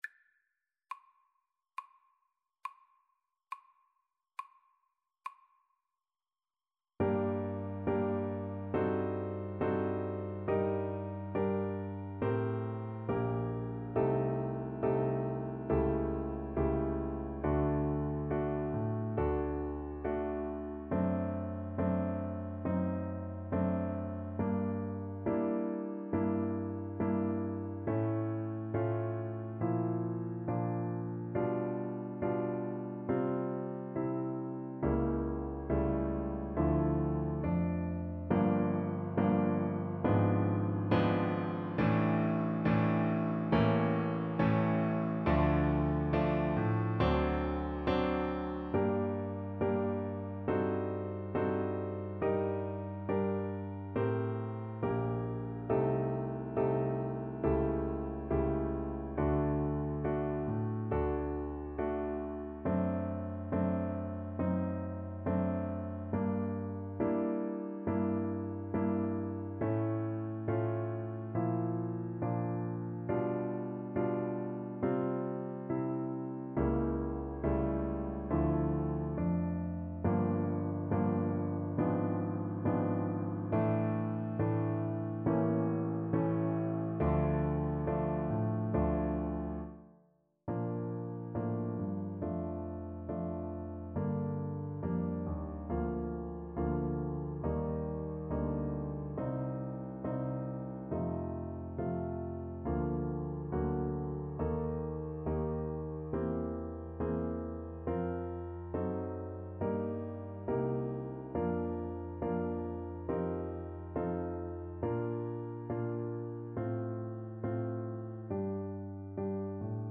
Violin version
4/4 (View more 4/4 Music)
~ = 100 Grave (=69)
Classical (View more Classical Violin Music)